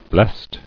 [blest]